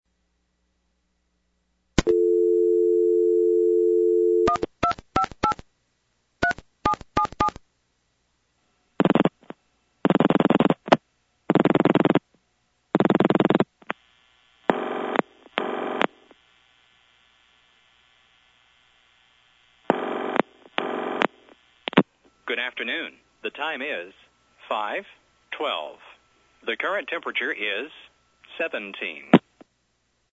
These were made over the Collector’s network  (C-Net) using an Analog Telephone Adapter (ATA) via the Internet.
Time and Temperature (November 2024) (also available on the PSTN at 218-488-TIME)